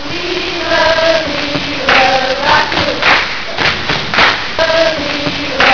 Die Praxisstunden werden jahrgangsübergreifend in Neigungsgruppen (Chor, Orchester, Blockflötenensemble) erteilt.
Gruppe, Chor [51K wav]
chor.wav